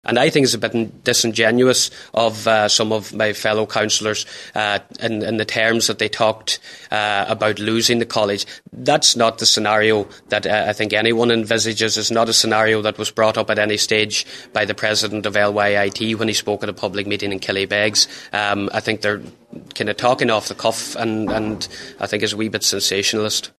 Some councillors suggested the campus was on its last legs and could be lost to Sligo or Galway if not moved to Letterkenny but Councillor Campbell says those suggestions sensationalising the issue: